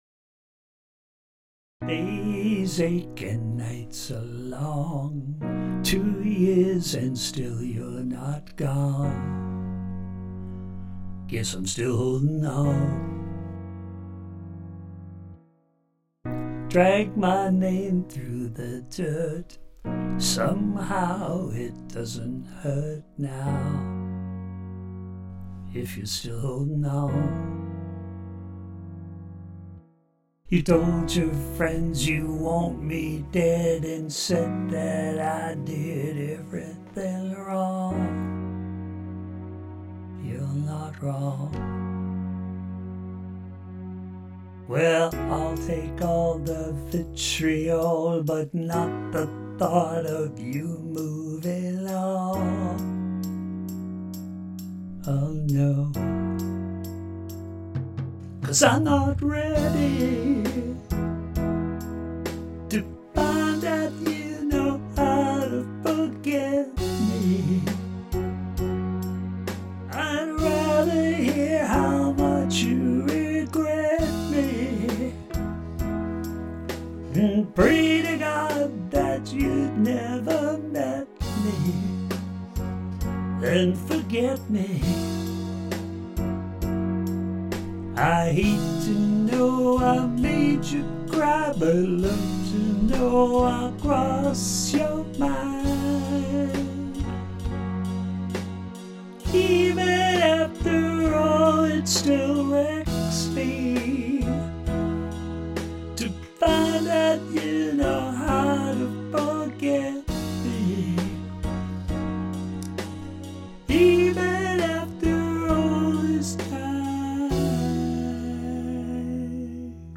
In which I sounds like a harpooned whale.